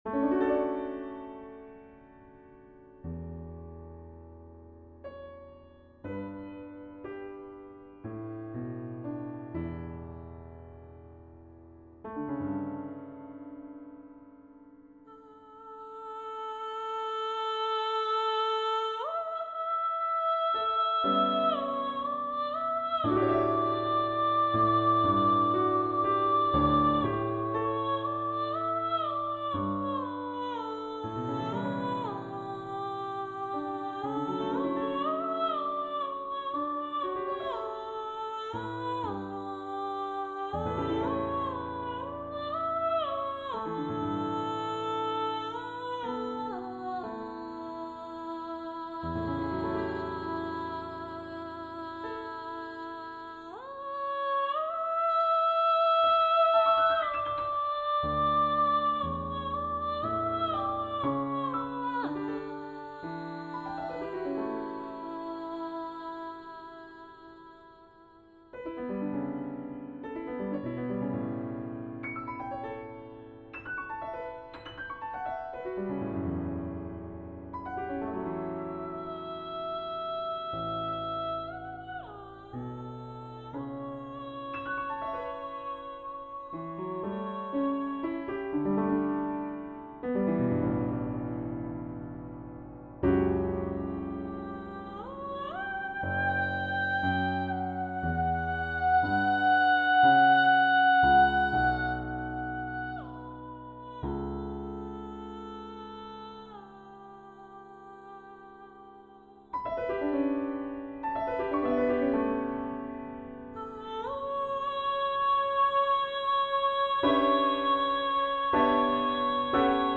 Echoes of Seikilos - Choral, Vocal - Young Composers Music Forum
I tried to give it a flavour of Antiquity, and to create unexpected harmonies.